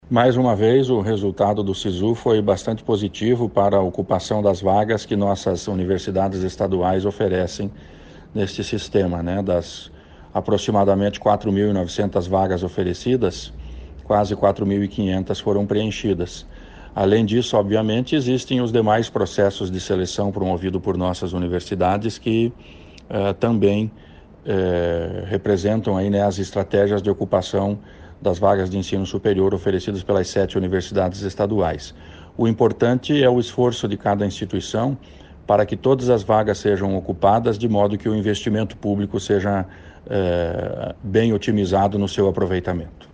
Sonora do secretário da Ciência, Tecnologia e Ensino Superior do Paraná, Aldo Nelson Bona, sobre a seleção de candidatos para as universidades estaduais do Paraná pelo Sisu | Governo do Estado do Paraná